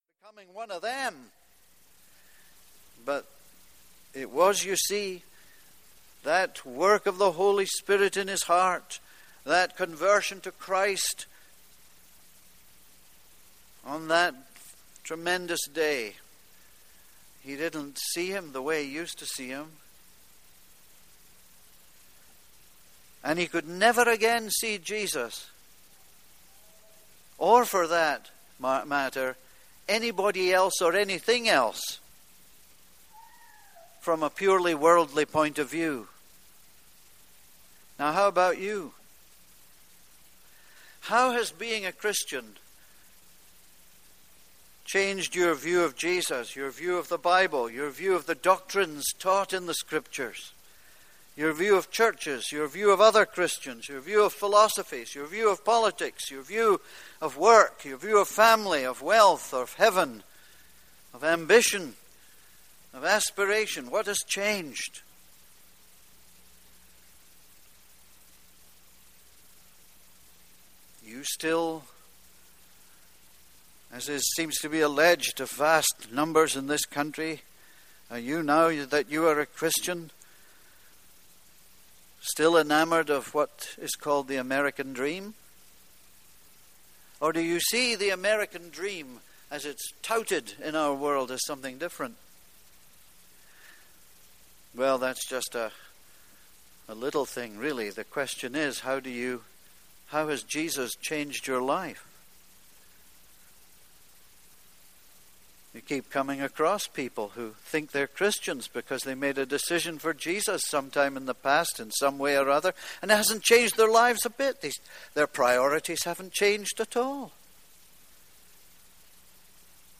This is a sermon on 2 Corinthians 5:16-6:2.